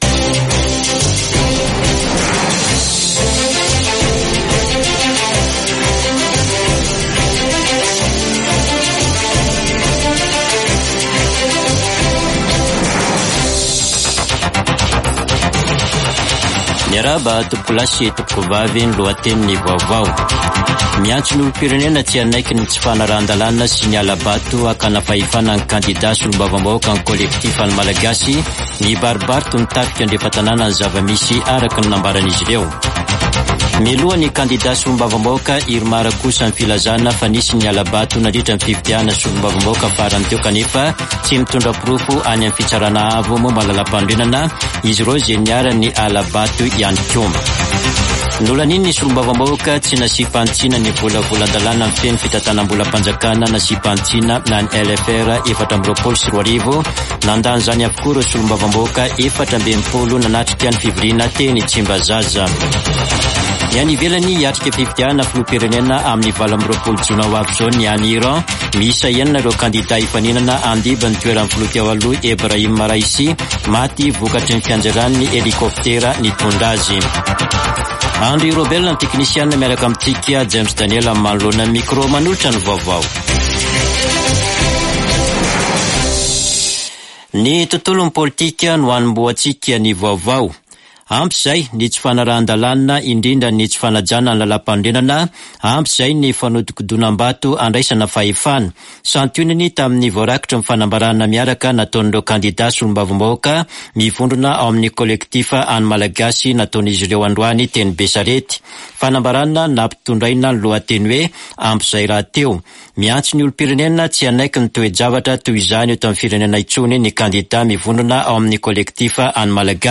[Vaovao hariva] Alatsinainy 10 jona 2024